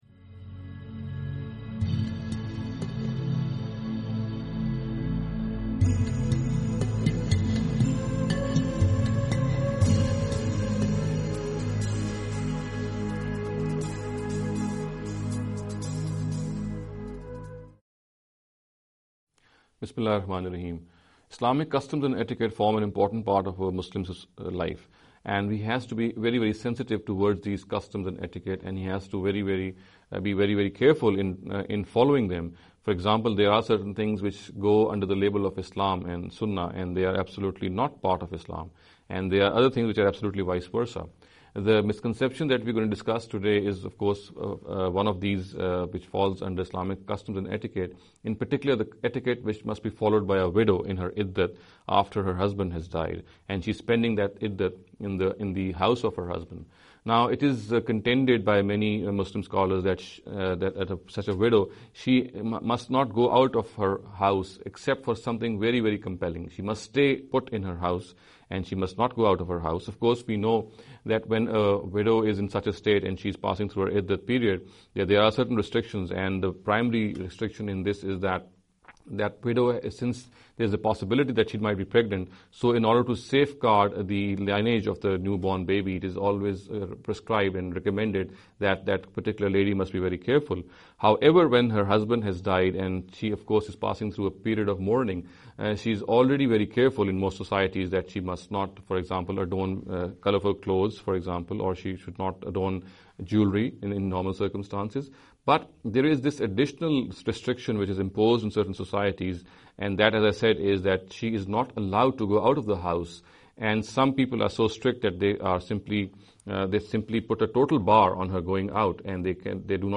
This lecture series will deal with some misconception regarding the Islamic Customs & Etiquette. In every lecture he will be dealing with a question in a short and very concise manner. This sitting is an attempt to deal with the question 'Iddat Restrictions for Widows’.